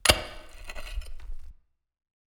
ajout des samples de pioche
pickaxe_1.wav